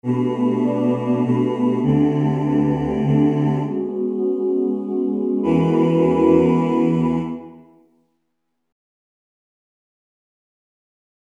Key written in: E Major